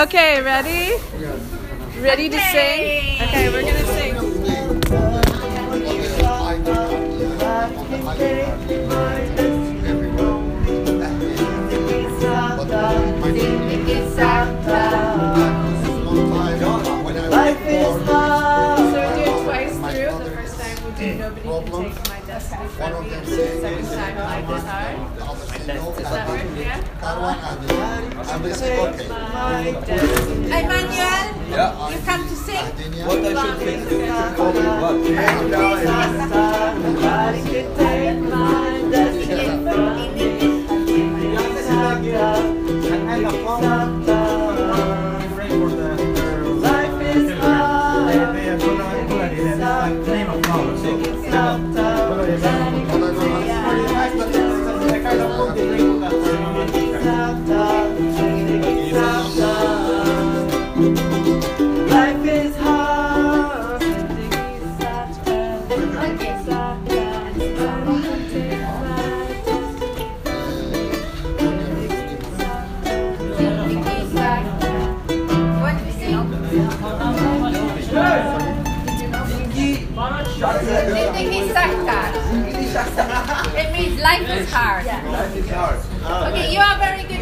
There is room for musical instruments to solo as well, particularly as the song has a kind of jazzy, blues feel to it.
The song is a chanting of the Dari phrase Zindgi Sakhta, which means Life is hard.
These are recordings from the center that show the process of writing the song:
life-is-hard-resident-singing.m4a